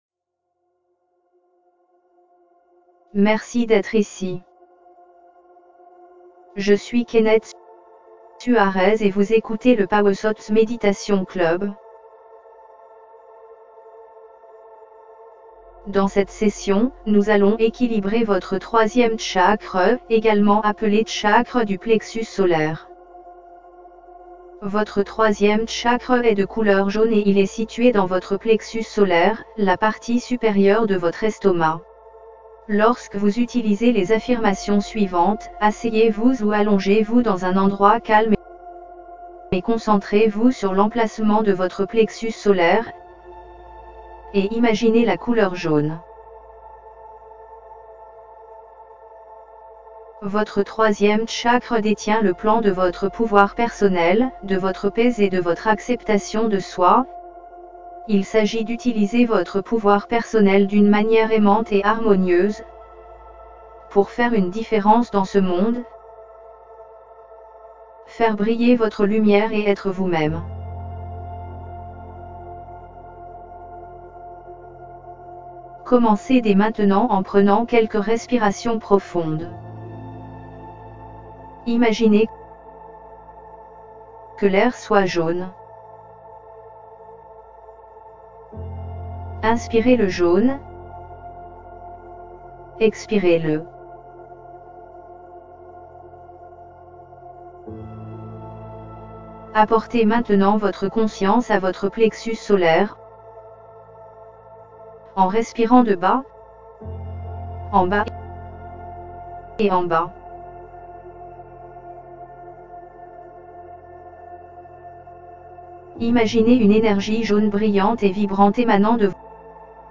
3ActivatingQiFlowOfSolarPlexusChakraMeditationFR.mp3